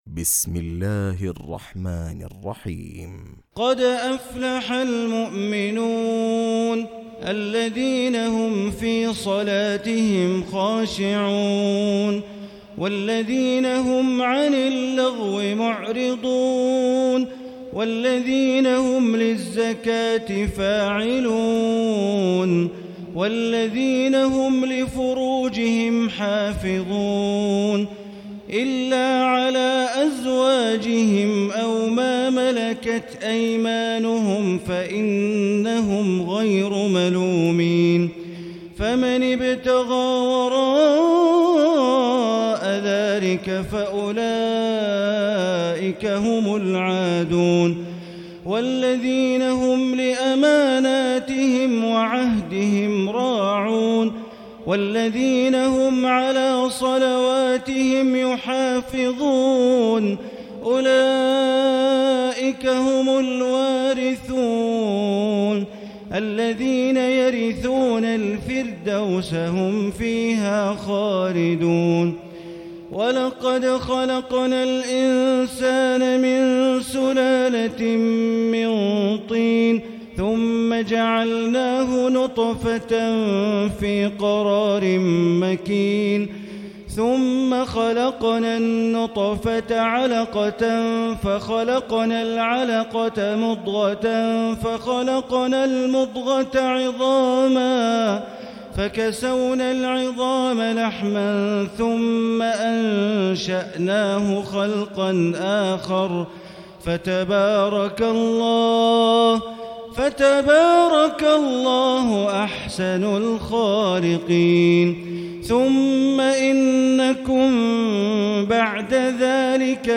تراويح الليلة السابعة عشر رمضان 1439هـ سورتي المؤمنون و النور (1-20) Taraweeh 17 st night Ramadan 1439H from Surah Al-Muminoon and An-Noor > تراويح الحرم المكي عام 1439 🕋 > التراويح - تلاوات الحرمين